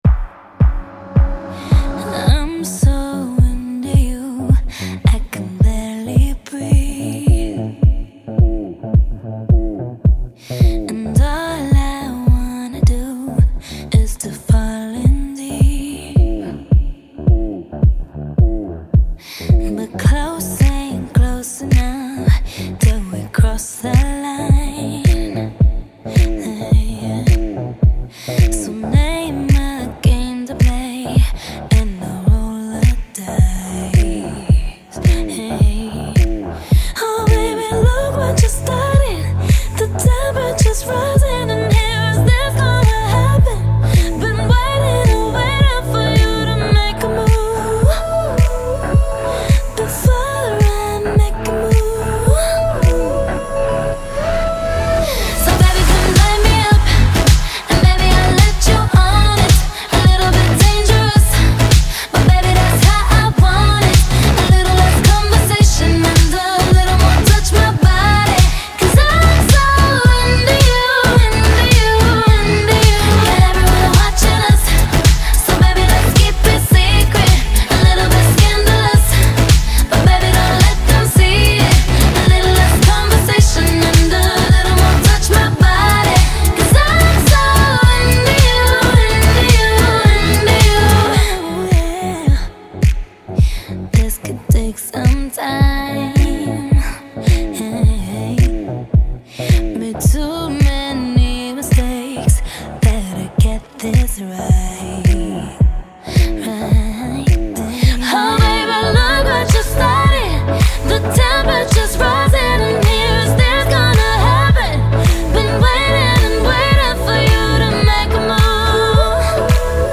a fun, flirty,  natural jam
an absolute banger